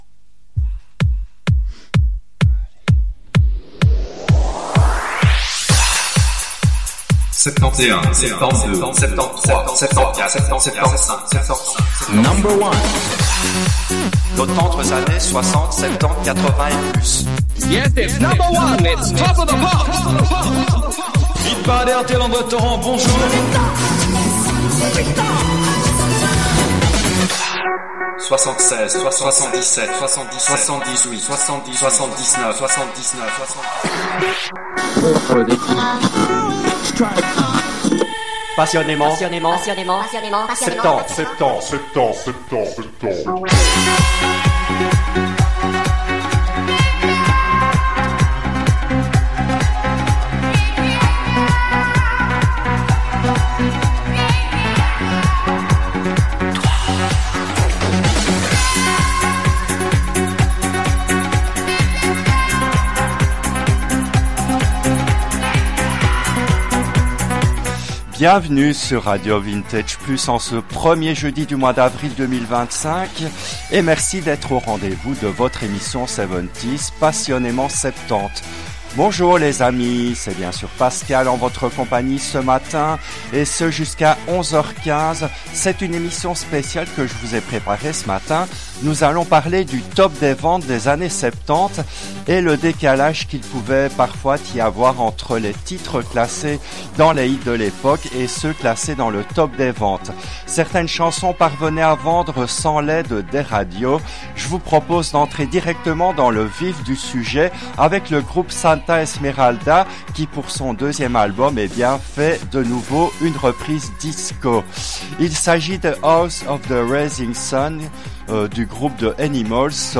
L’émission a été diffusée en direct le jeudi 03 avril 2025 à 10h depuis les studios belges de RADIO RV+.